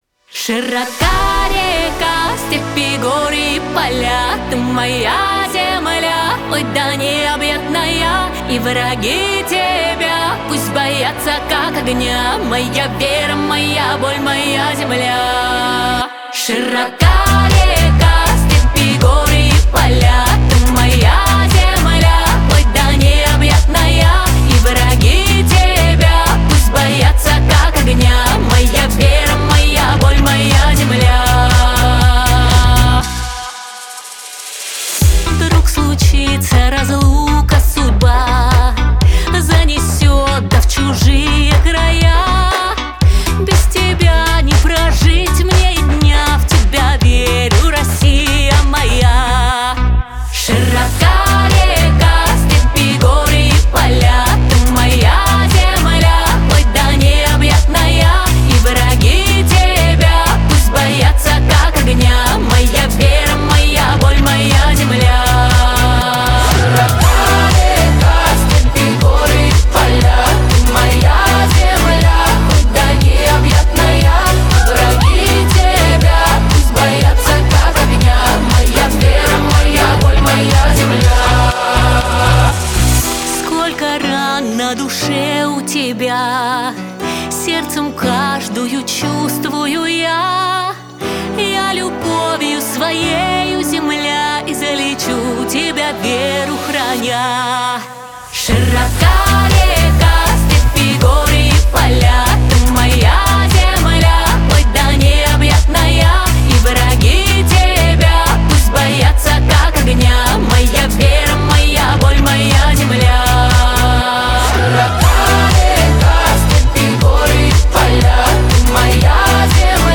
14 декабрь 2025 Русская AI музыка 130 прослушиваний